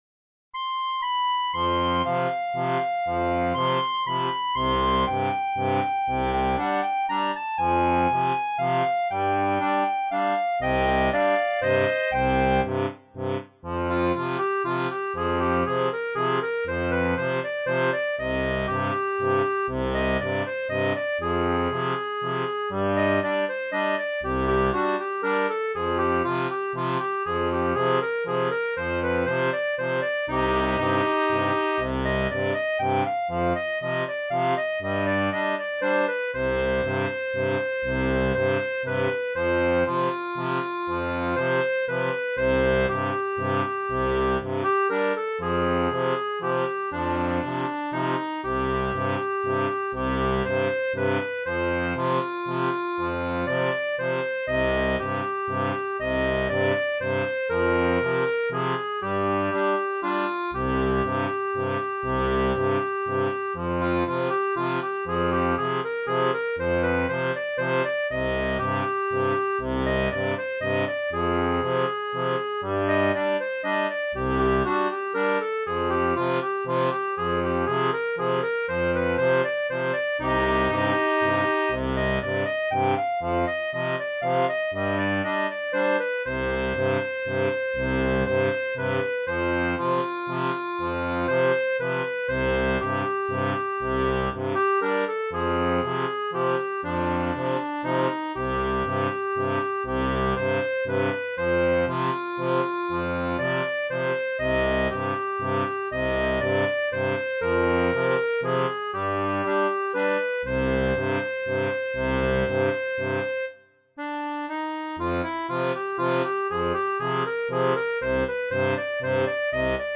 • une version pour accordéon diatonique à 3 rangs
Chanson française